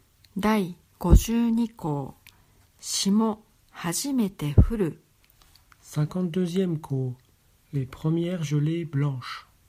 Les 72 saisons — lecture en japonais et français